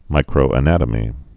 (mīkrō-ə-nătə-mē)